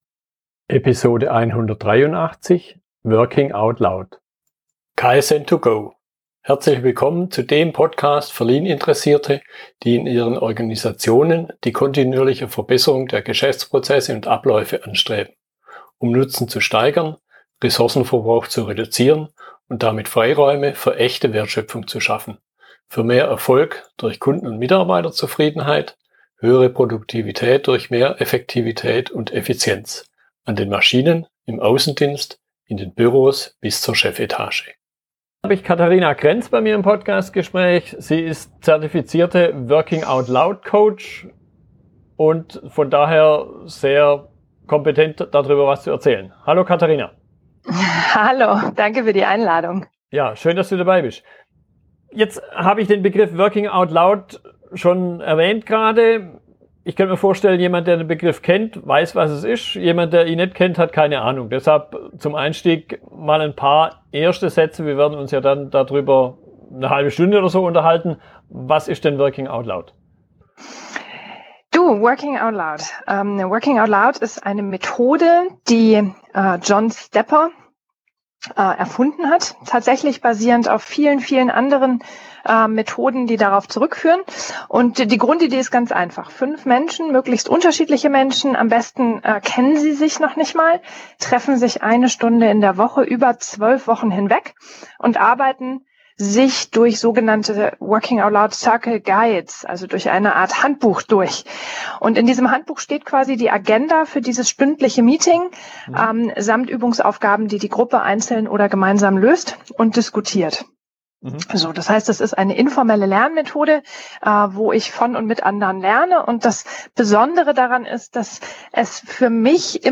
Unterhaltung